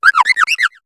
Cri de Victini dans Pokémon HOME.